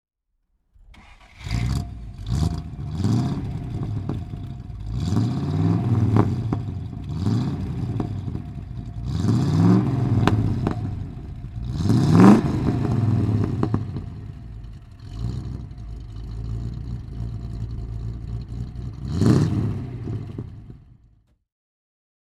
Motorsounds und Tonaufnahmen zu MG Fahrzeugen (zufällige Auswahl)
MGB GT V8 (1974) - Starten und Leerlauf
MGB_GT_V8_1974.mp3